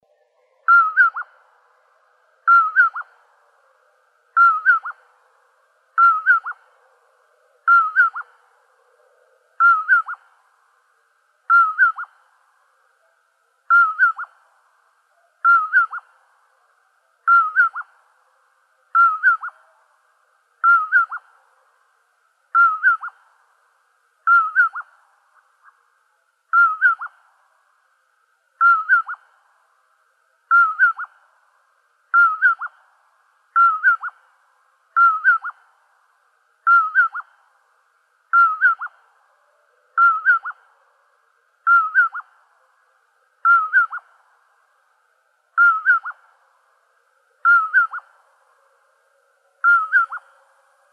content/hifi-public/sounds/Animals/commonpoorwill.wav at main
commonpoorwill.wav